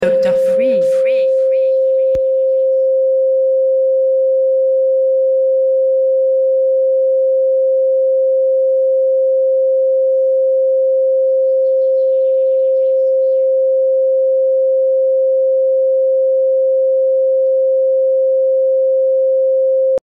Régénérez vos cellules avec 528 Hz La fréquence 528 Hz est reconnue dans le solfège sacré pour soutenir la régénération cellulaire et favoriser l’harmonie de l’ADN. Elle agit en douceur, apportant un sentiment d’équilibre et de vitalité.